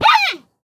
assets / minecraft / sounds / mob / panda / hurt6.ogg
hurt6.ogg